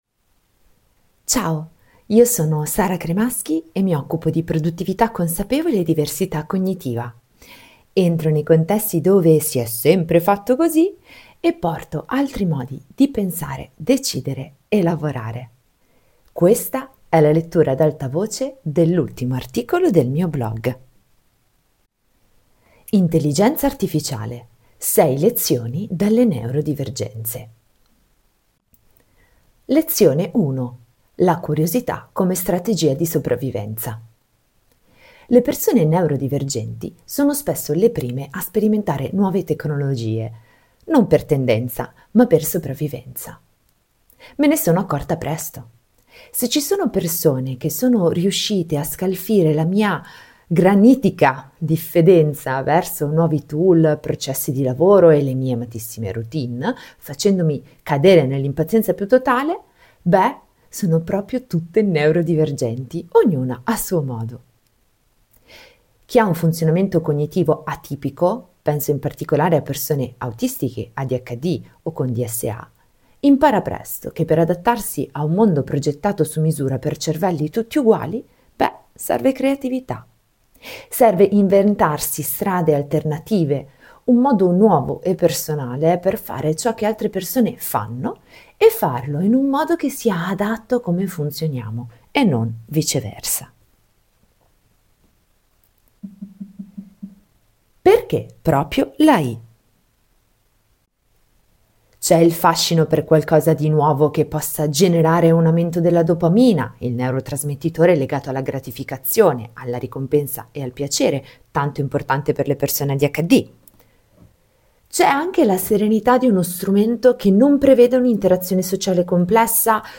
Se non ti va di leggere questo articolo, puoi ascoltarlo . Non sono una lettrice ad alta voce professionista, mi scapperà qualche papera e devo ancora trovare gli strumenti giusti.